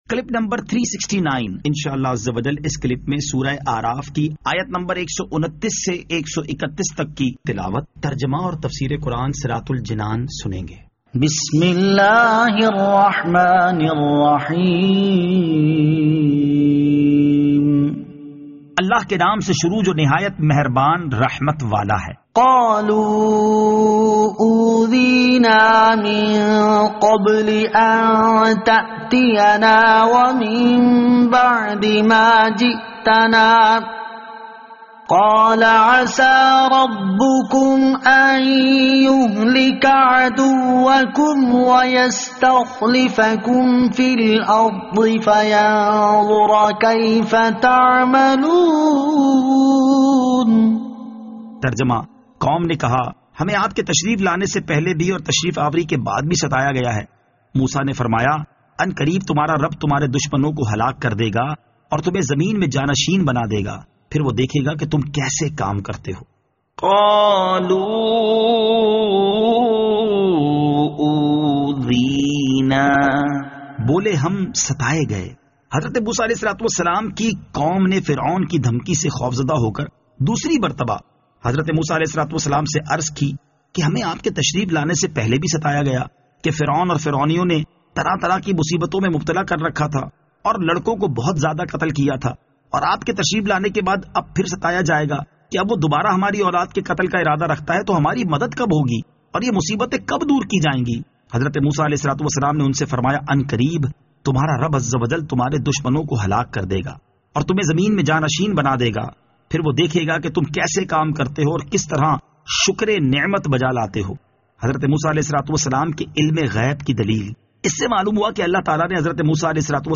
Surah Al-A'raf Ayat 129 To 131 Tilawat , Tarjama , Tafseer